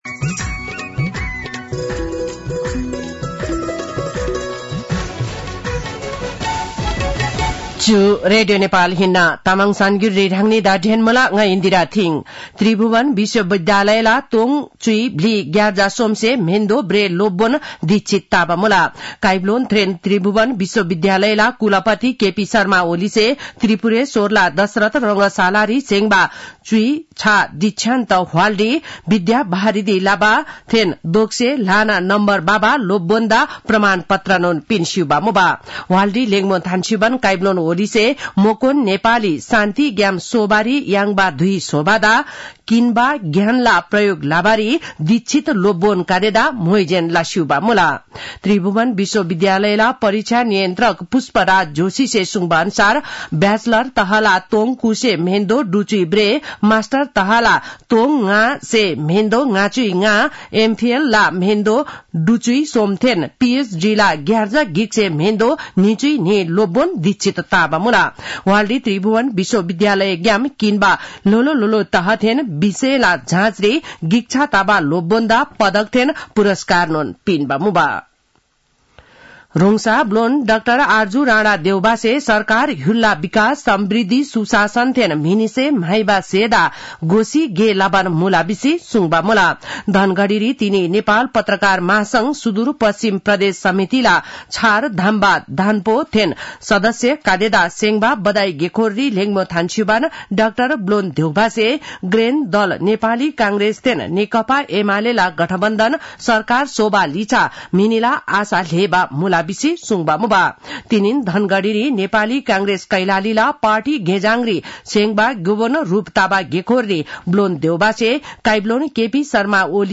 तामाङ भाषाको समाचार : १४ पुष , २०८१